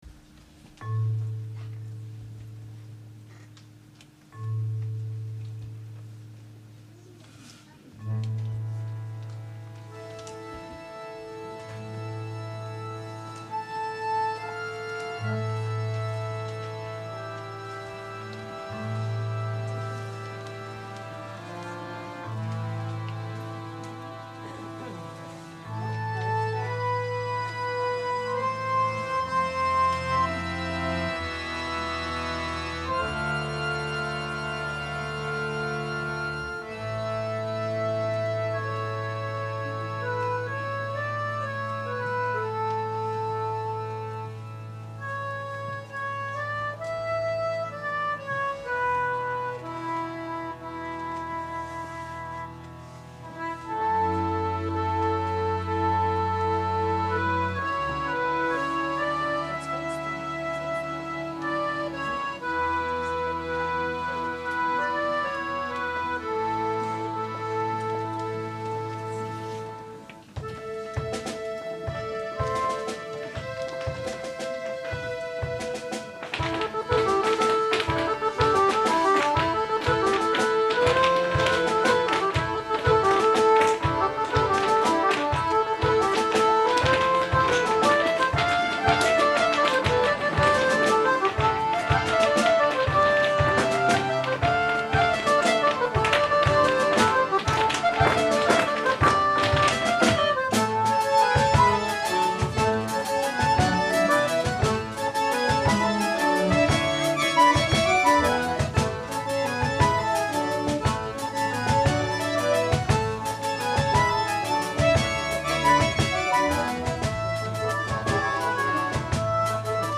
1.Akkordeon-Orchester Rheinhausen 1950 e.V.
14.November 2004: Vereinskonzert
„Celtic Feet“, Irish Step Dance